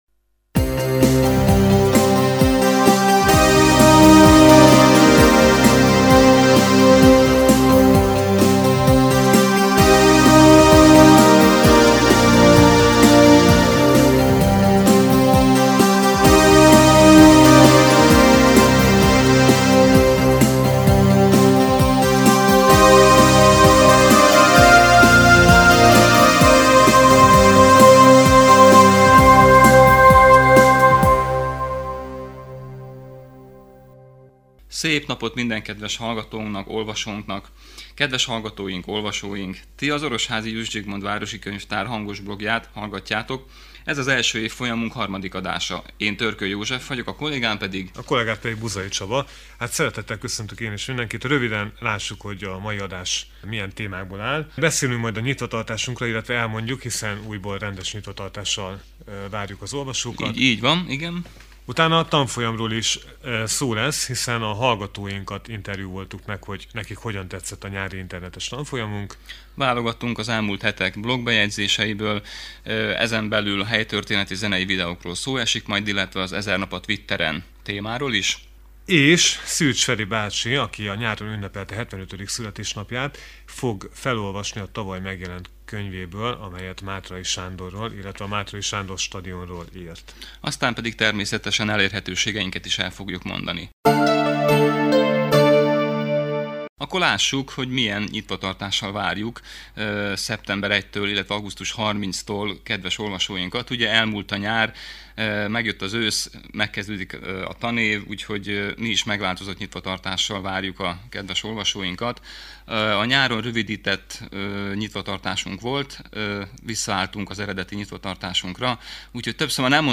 Tanfolyamot tartottunk – hallgatói visszajelzéseket kértünk (interjúk)